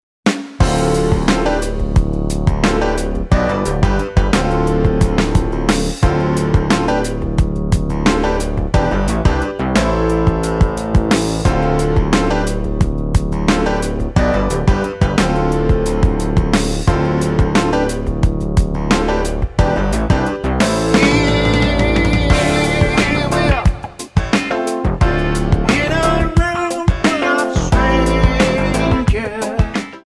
--> MP3 Demo abspielen...
Tonart:Dm Multifile (kein Sofortdownload.
Die besten Playbacks Instrumentals und Karaoke Versionen .